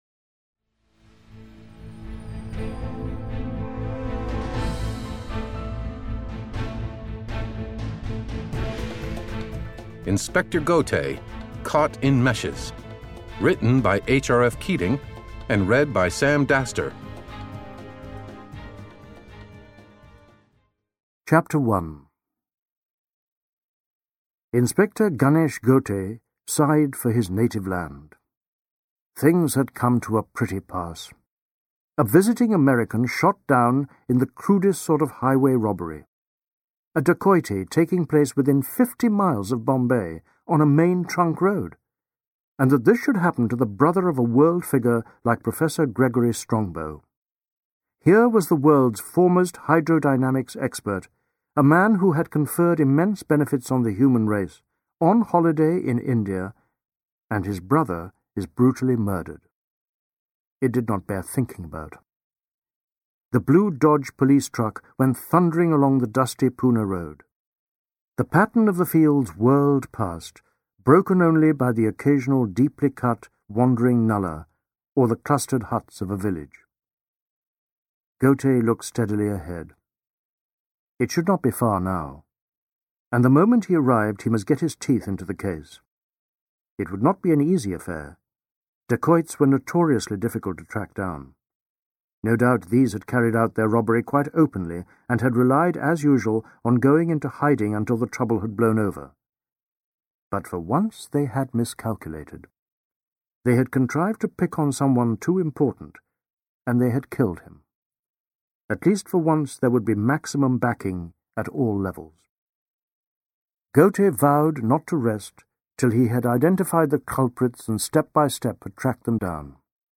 Unabridged MP3 CD Audio Book